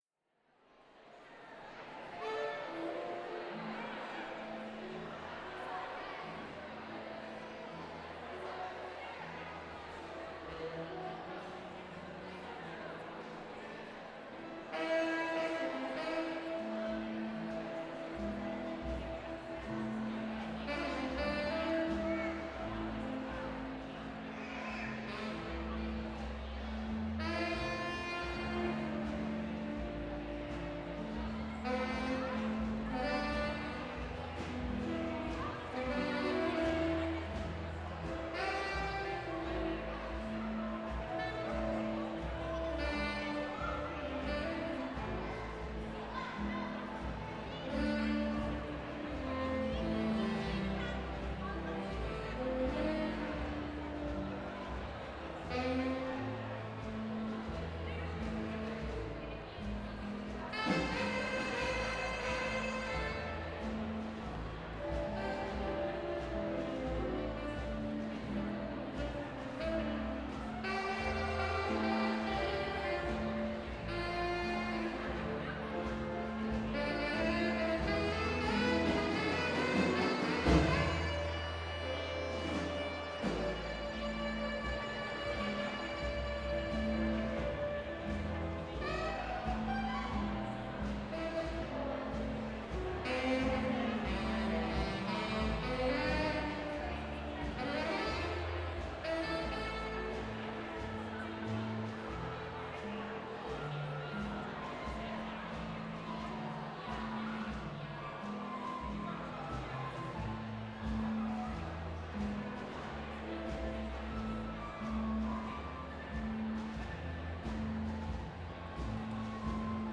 There was a "Rock & Roll Ceilidh" held in Oxford Town Hall to celebrate new facilities for cancer research in the university. This selection is a quiet saxophone solo (Gershwin's Summertime) during the buffet. The microphones were suspended over the dance floor between the musicians and the food.
Ambisonic
Ambisonic order: H (3 ch) 1st order horizontal
Microphone name: homemade
Array type: Native B-format
Capsule type: AKG Blue Line